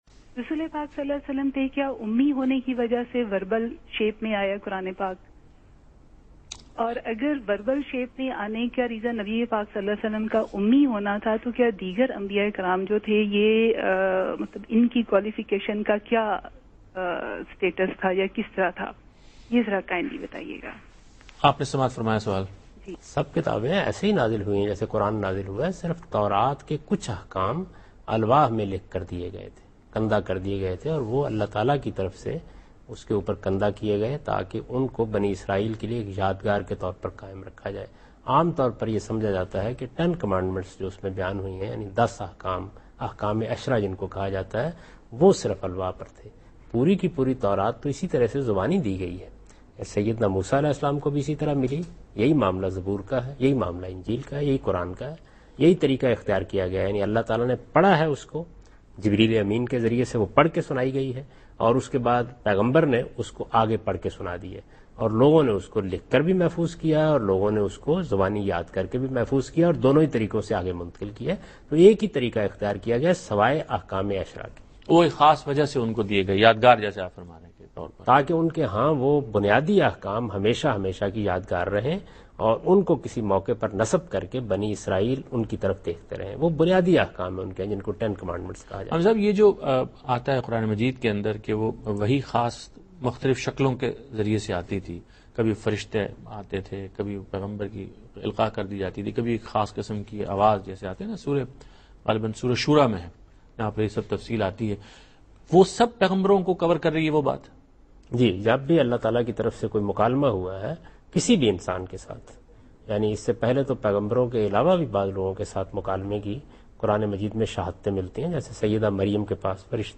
Category: TV Programs / Dunya News / Deen-o-Daanish /
Javed Ahmad Ghamidi answers a question about "The Unlettered Prophet and the Qur'an's Verbal Revelation" in program Deen o Daanish on Dunya News.